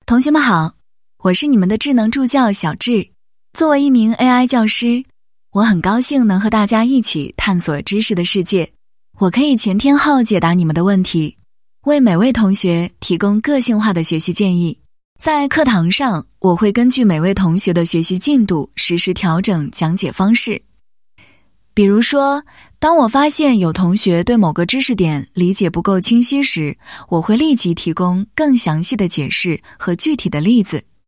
Warm and Magnetic.mp3